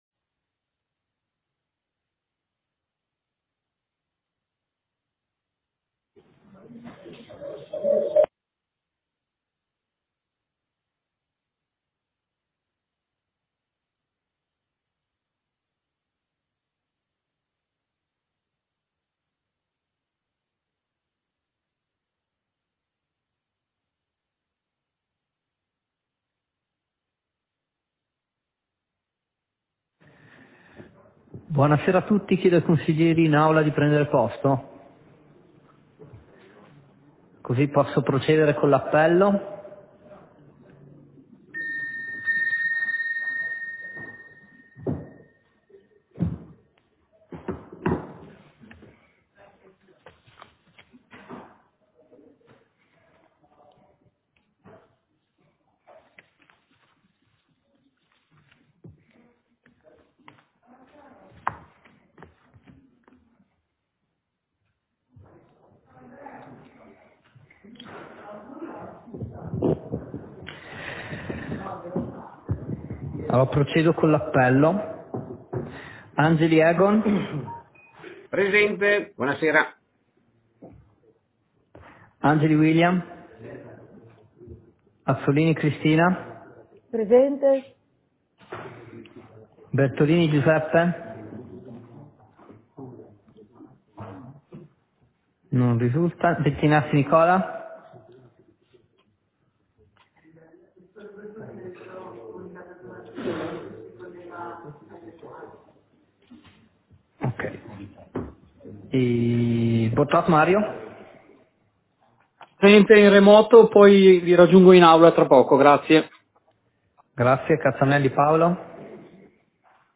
Seduta del consiglio comunale - 05.04.2022